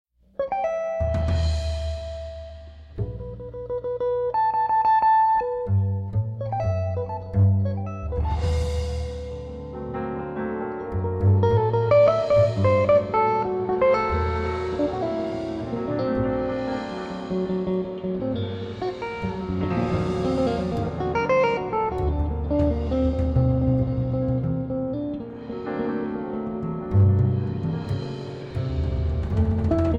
Guitar
Soprano Saxophone
Piano
Bass
Drums
• Movement 1 Improvisation: